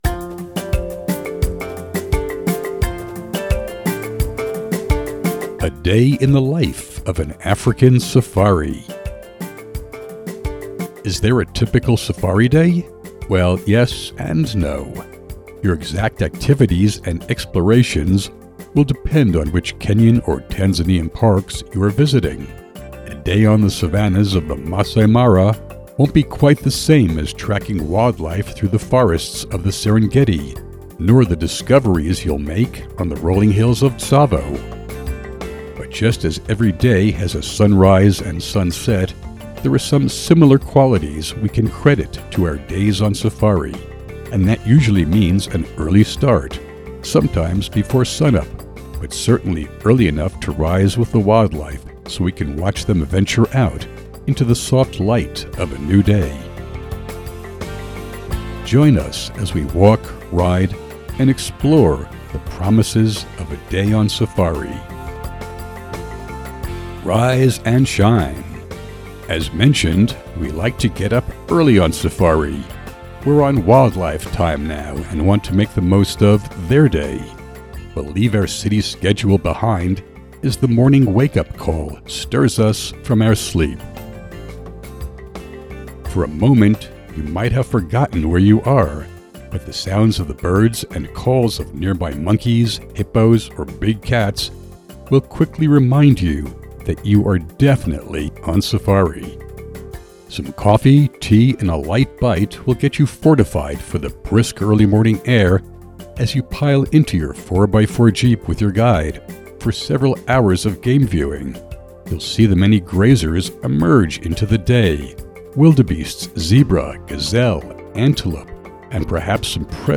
Listen to an audio version of this blog post!